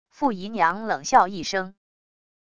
付姨娘冷笑一声wav音频生成系统WAV Audio Player